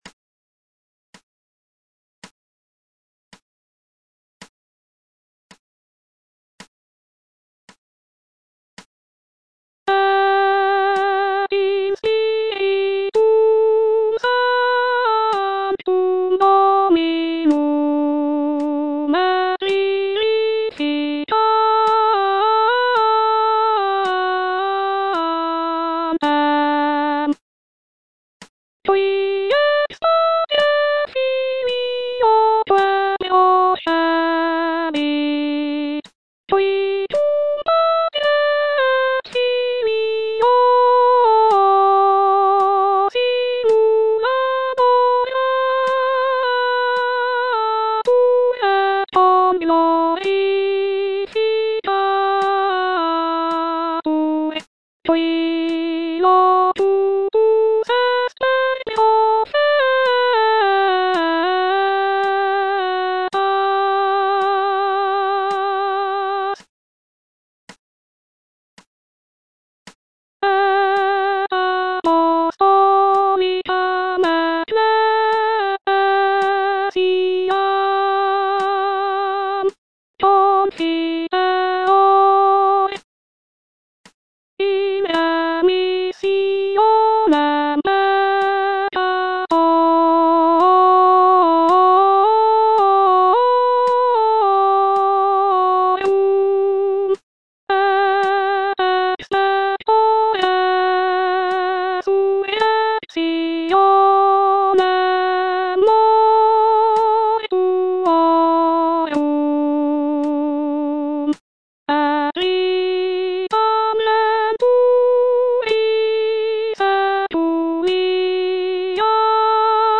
G.P. DA PALESTRINA - MISSA REGINA COELI Credo (bar 148 - 211) - Soprano (Voice with metronome) Ads stop: auto-stop
"Missa Regina coeli" is a choral work composed by Giovanni Pierluigi da Palestrina, a renowned Italian Renaissance composer. It is a polyphonic setting of the Catholic Mass, specifically the "Missa sine nomine" (Mass without a name). The composition is characterized by Palestrina's signature style, known for its smooth and flowing melodic lines, balanced harmonies, and careful attention to text setting.